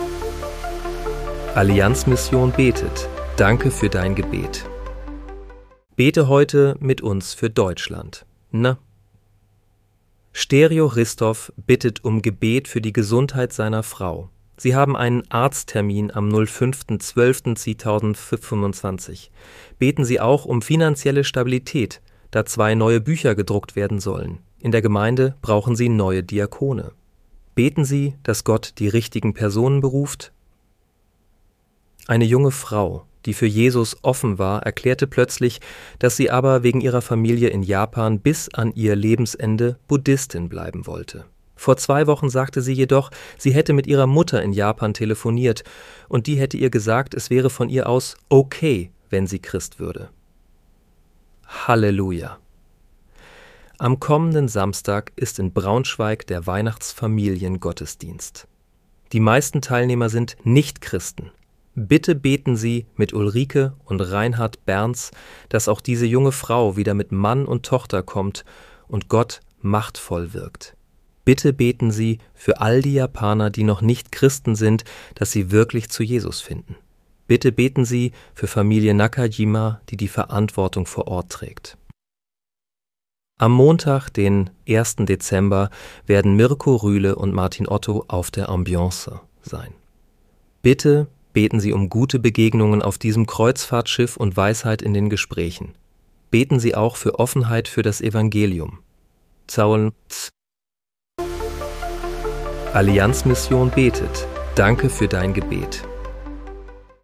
Bete am 05. Dezember 2025 mit uns für Deutschland. (KI-generiert